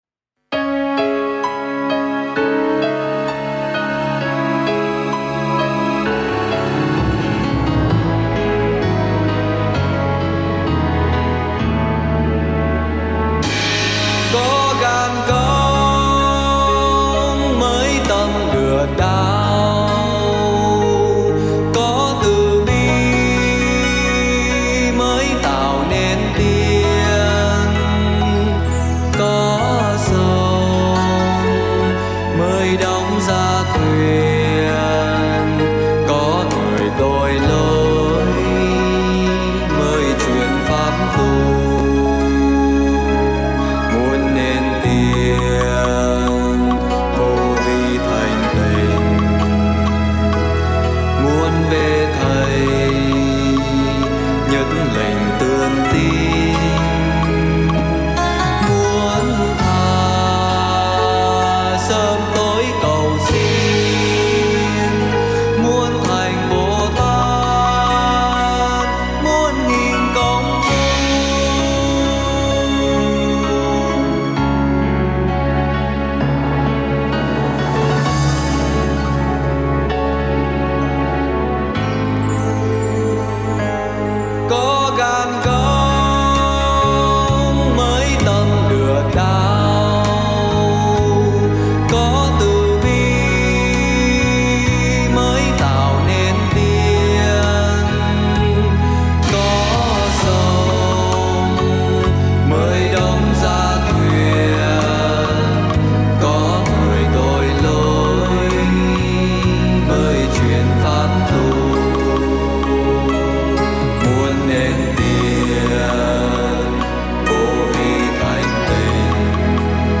Thể Loại Đạo Ca: Đạo ca Cao Đài